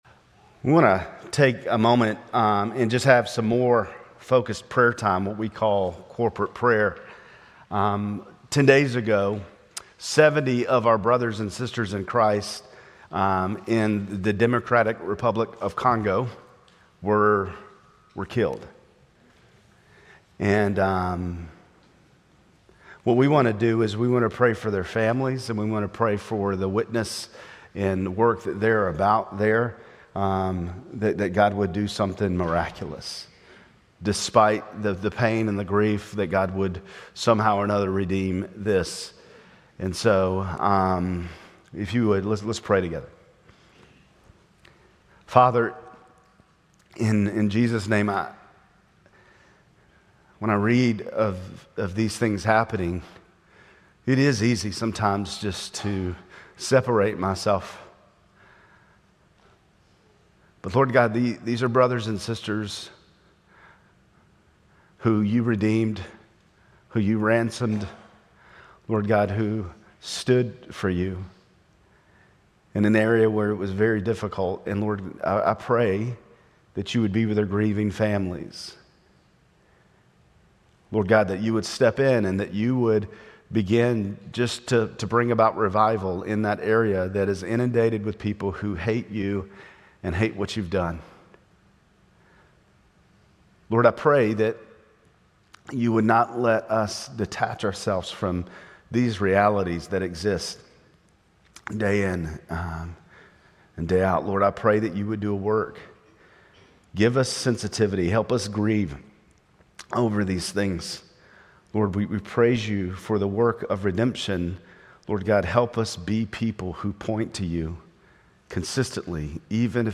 Grace Community Church Lindale Campus Sermons 2_23 Lindale Campus Feb 23 2025 | 00:29:48 1x 00:00 / 00:29:48 Subscribe Share RSS Feed Share Link Embed